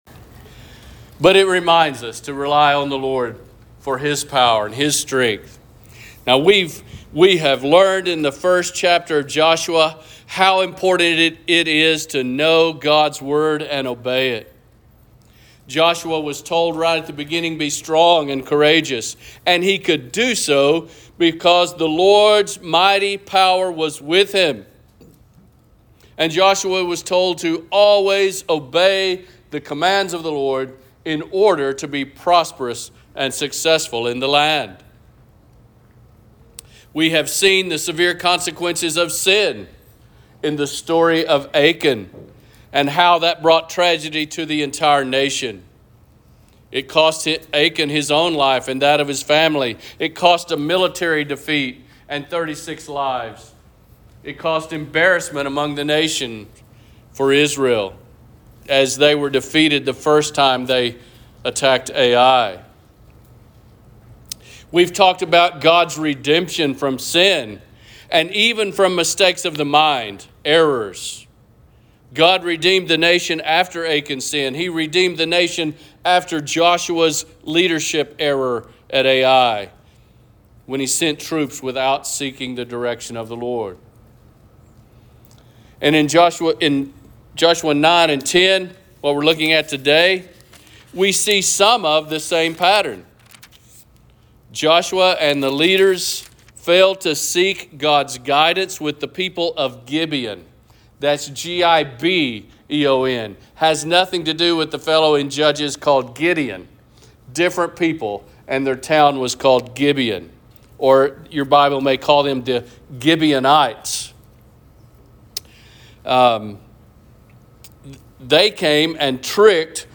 Sermons | Lawn Baptist Church